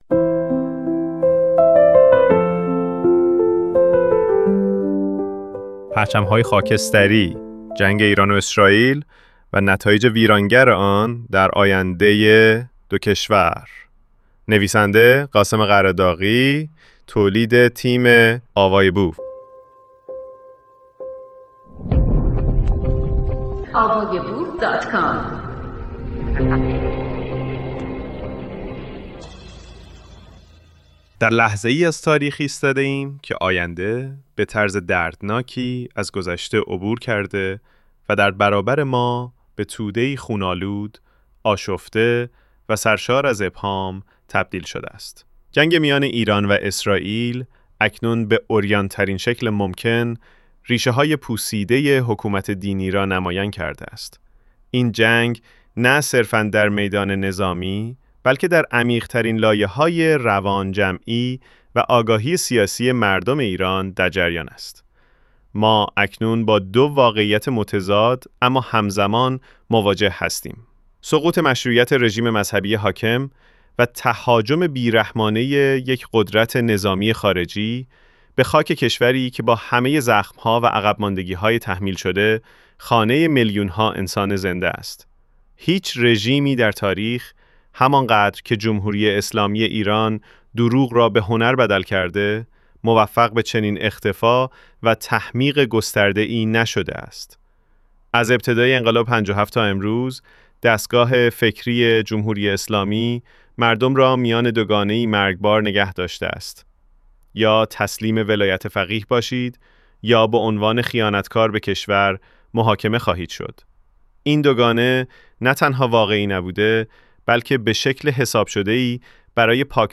مجموعه کتابهای صوتی